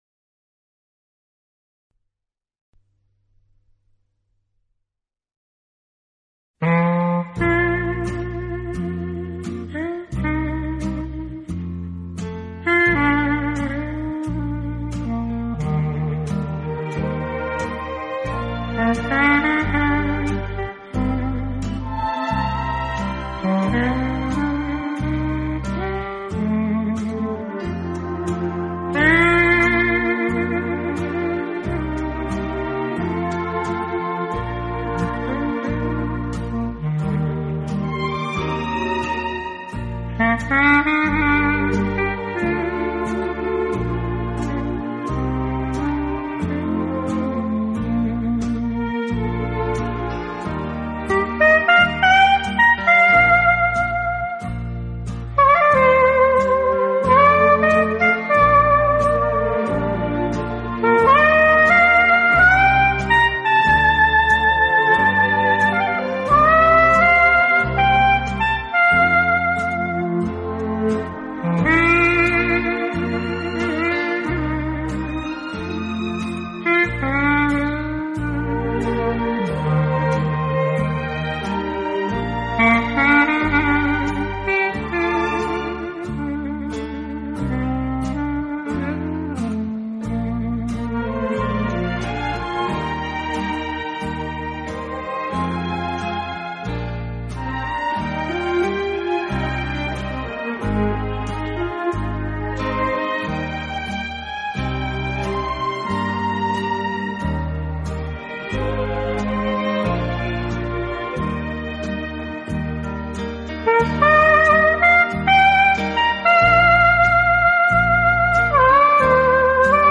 同时其录音又极其细致、干净、层次分明。
本专辑是该系列的单簧管曲。